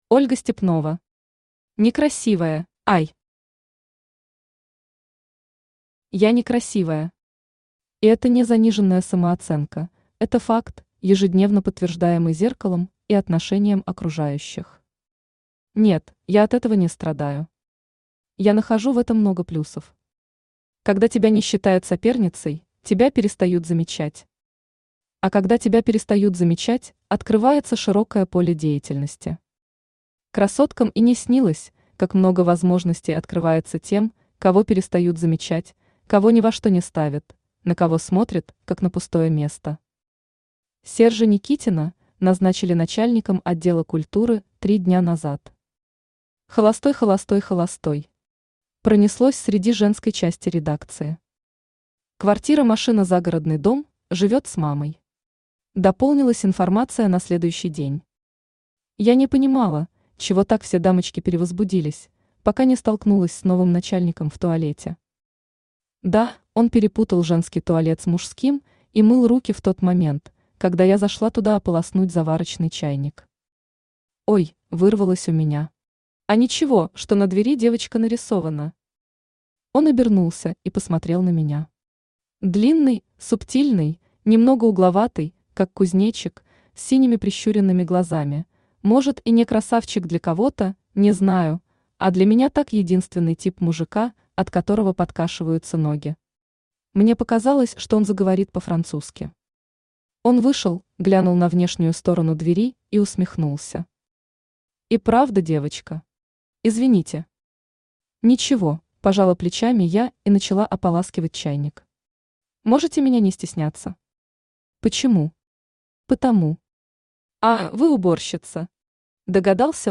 Аудиокнига Некрасивая | Библиотека аудиокниг
Aудиокнига Некрасивая Автор Ольга Степнова Читает аудиокнигу Авточтец ЛитРес.